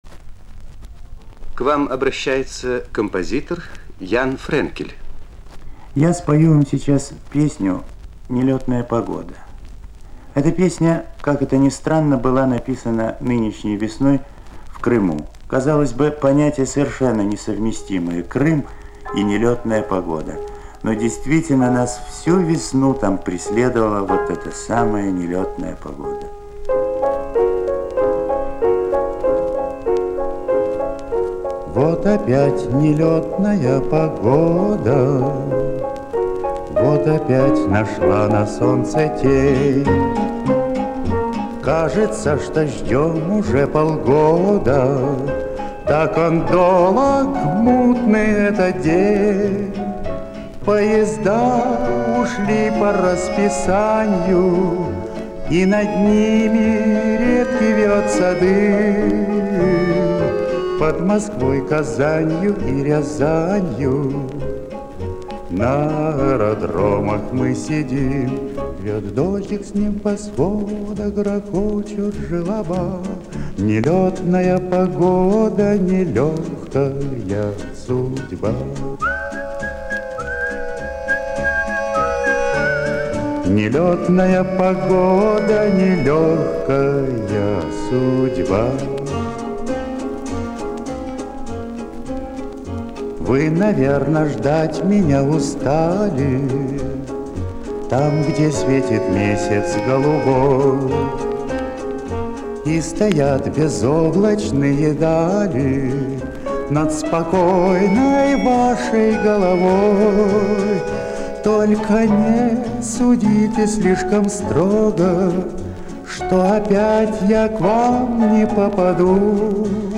Лирический рассказ.